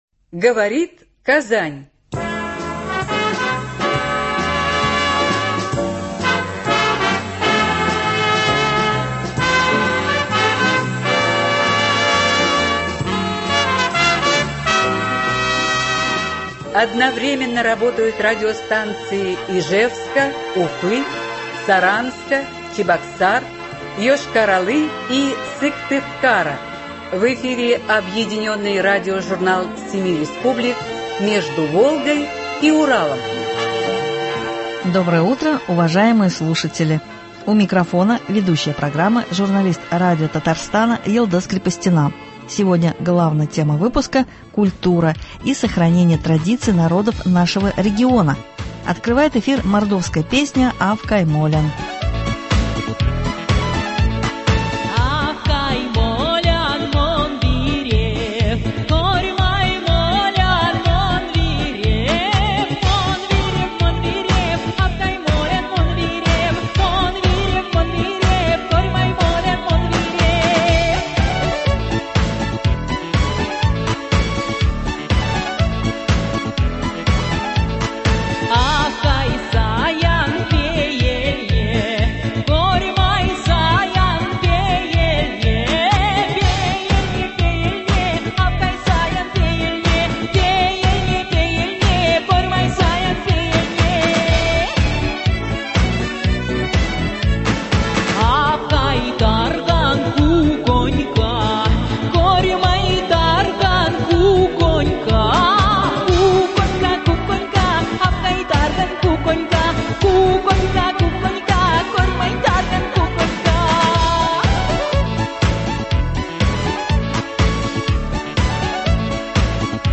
Объединенный радиожурнал 7 республик.